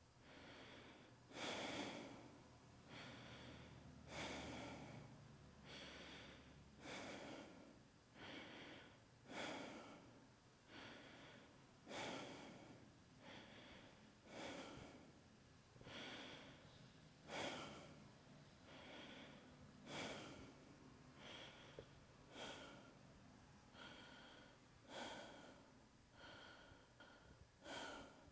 Breathing.wav